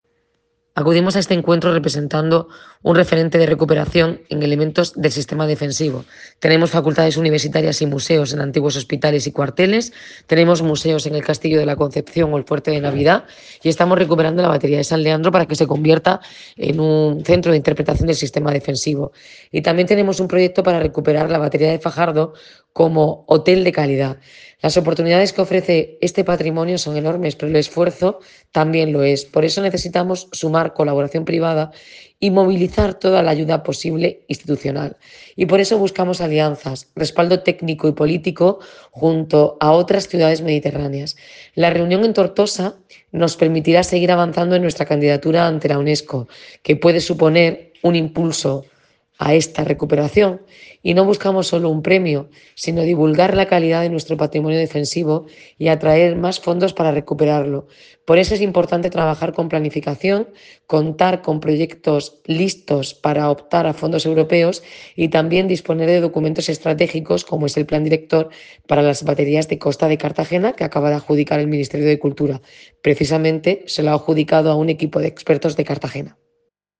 Enlace a Declaraciones de Noelia Arroyo sobre reunión proyecto Ciudades Fortificadas del Mediterráneo